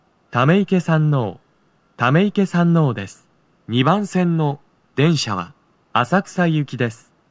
スピーカー種類 TOA天井型()
2番線 上野・浅草方面 到着放送 【男声